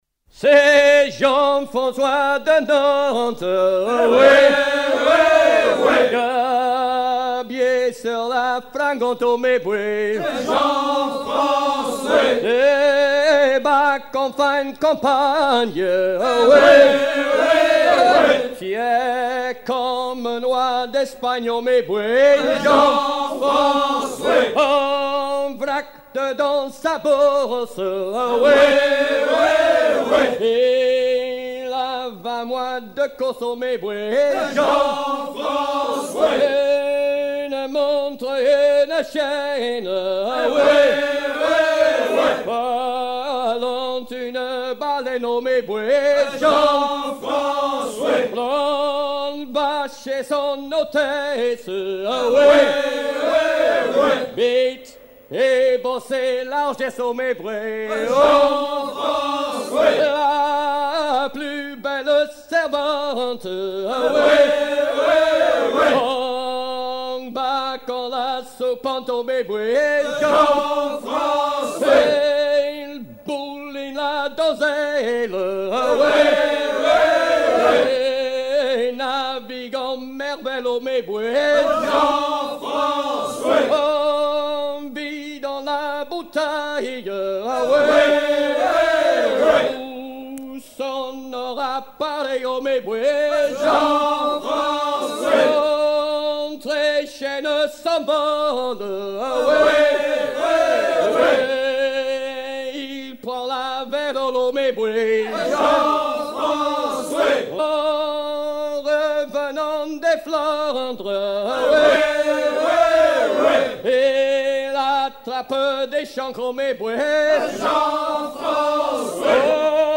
gestuel : à hisser main sur main
circonstance : maritimes
Chants de marins traditionnels
Pièce musicale éditée